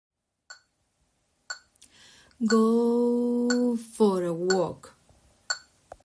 go_for_a_walk2.mp3